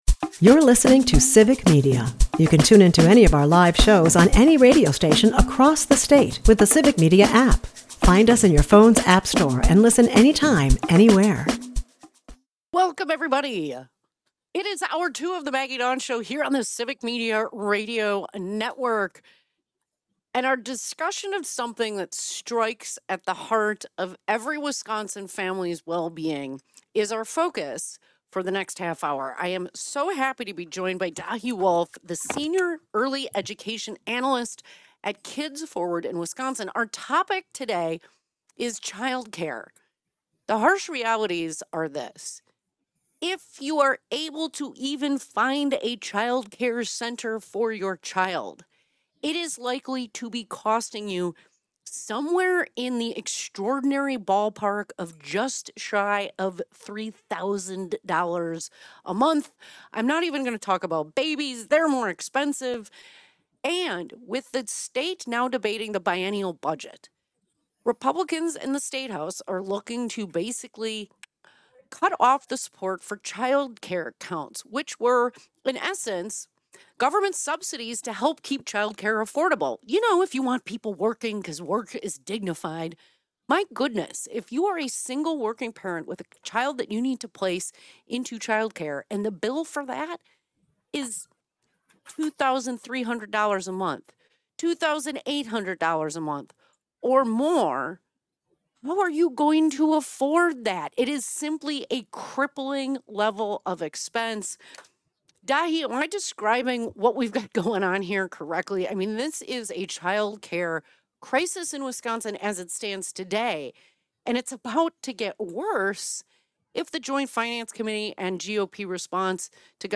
The conversation doesn’t shy away from criticizing GOP economic policies, branding them a “reverse Robin Hood” scheme that benefits the wealthy at the expense of working families.